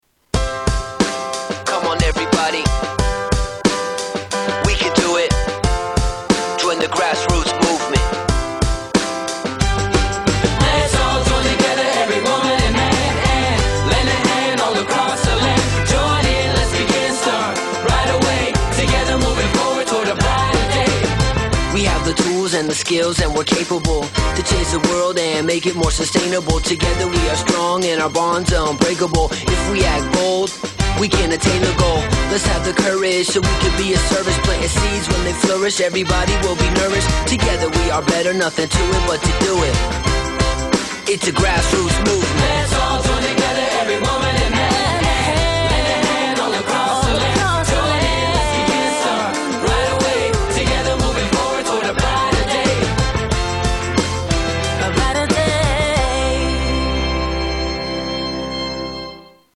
Tags: Starbucks Starbucks Commercial clips Starbucks clips Starbucks Ads Commercial